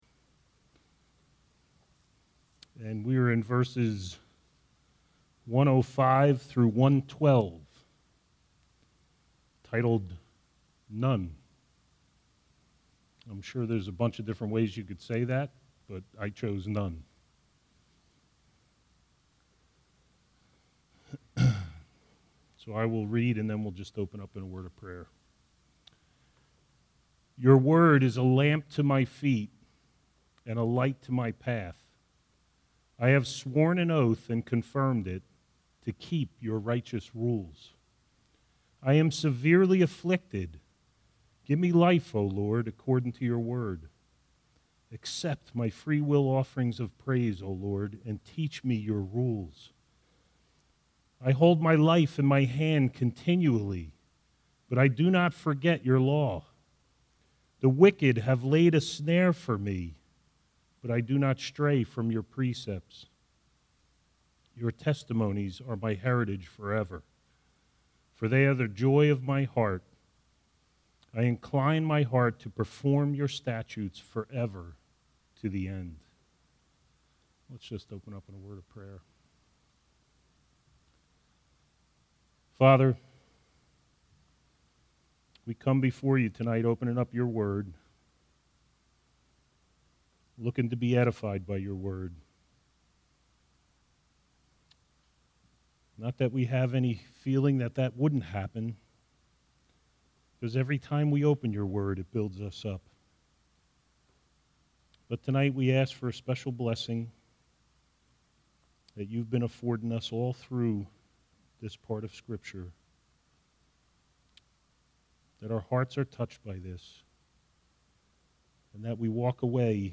All Sermons Psalm 119:105-112